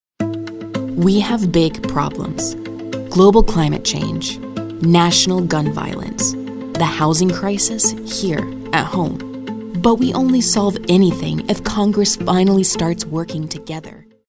authoritative, compelling, concerned, confident, informative, Matter of Fact, millennial, serious, young adult